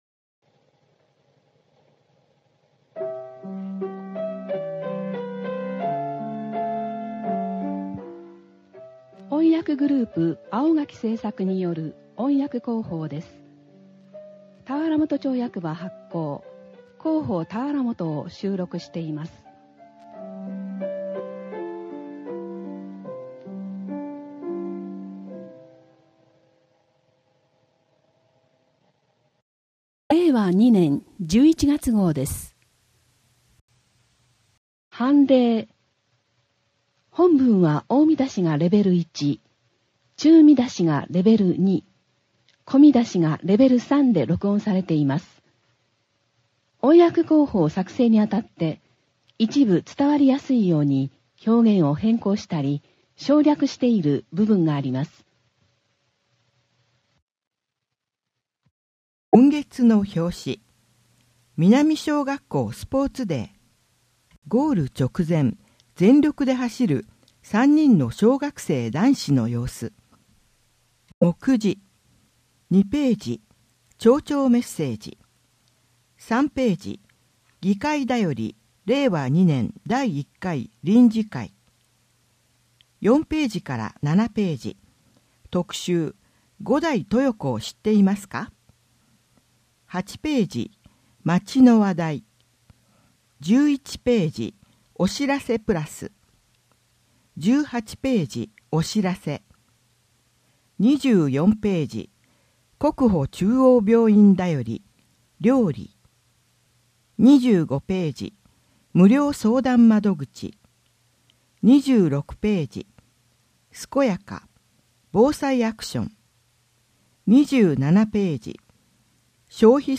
音訳広報たわらもと
音訳広報たわらもと1ページ (音声ファイル: 1.2MB)